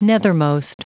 Prononciation du mot nethermost en anglais (fichier audio)
Prononciation du mot : nethermost